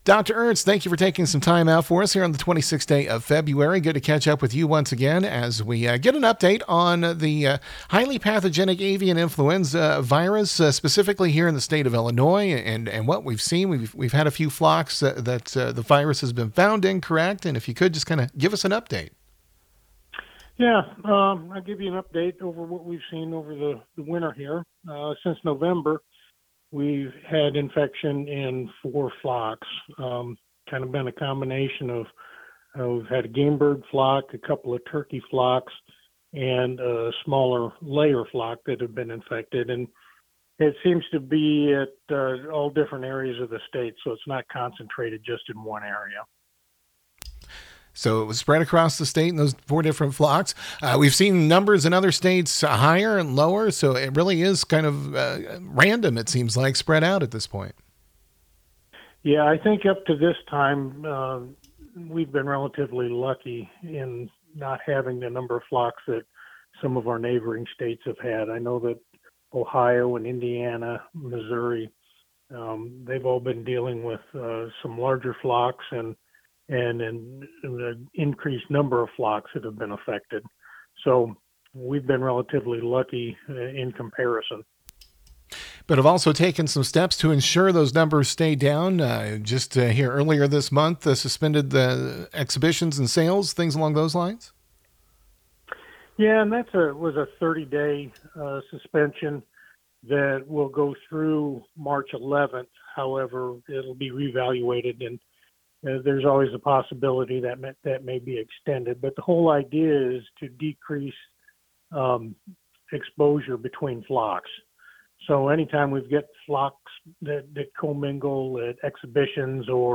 AUDIO: Dr. Mark Ernst – Illinois state veterinarian
2-26-25-Mark-Ernst.mp3